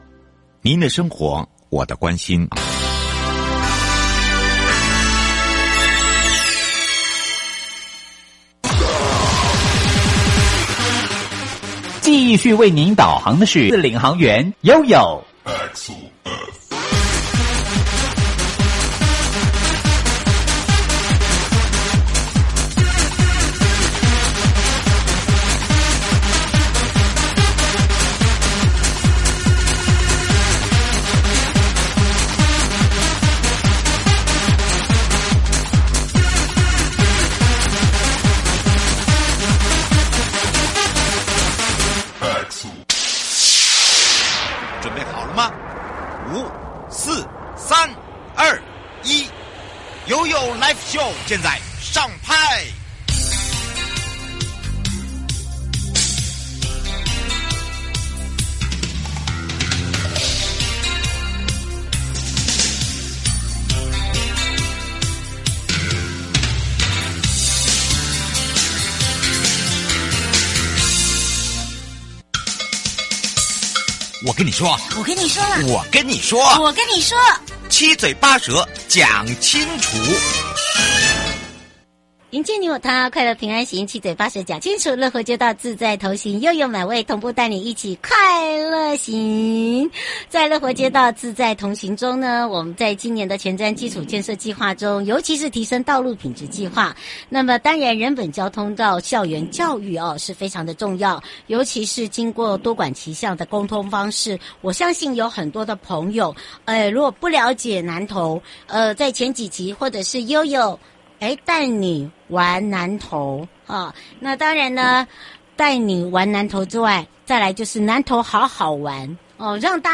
受訪者： 營建你我他 快樂平安行-主題「樂活街道自在同行」人本交通及校園教育宣導–(最終集) 南投營造人本交通